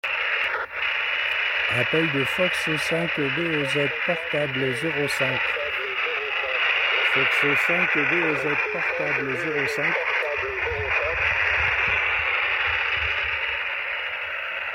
QSO ‘vacances’ ARA35 sur 7.123 Mhz LSB 18 juillet 2025
Antenne dipôle filaire, site sud de Rennes
Noter le décalage temporel du retour audio
dû au traitement du SDR plus le upload réseau web.